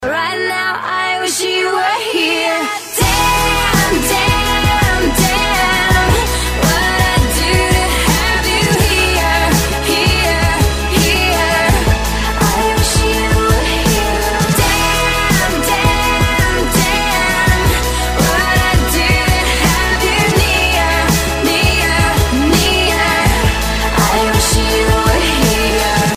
Категория: Rock